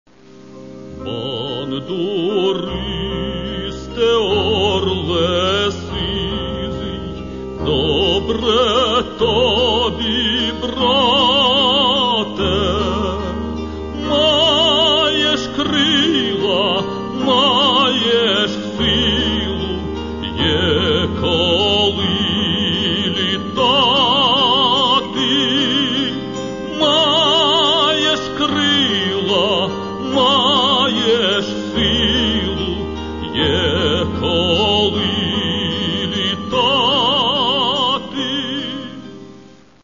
Каталог -> Народна -> Бандура, кобза тощо
Цього ж разу – все чисто, все просто. Вірші, голос, бандура.